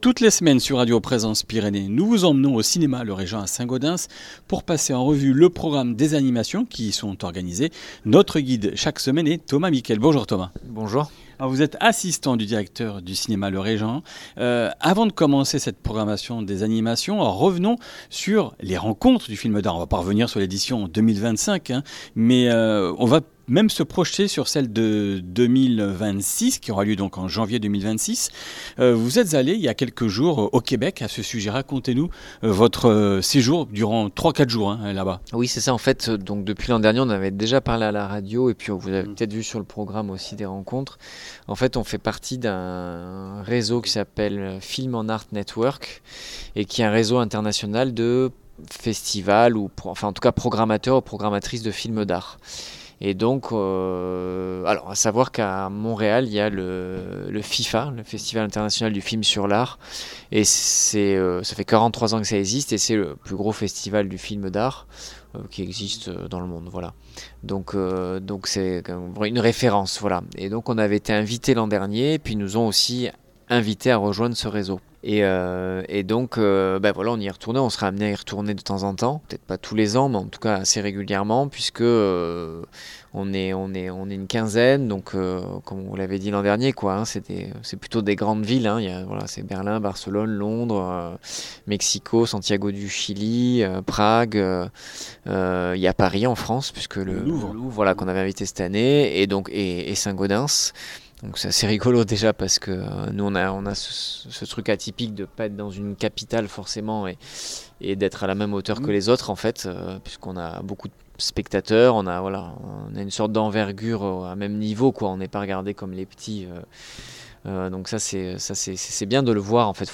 Comminges Interviews du 27 mars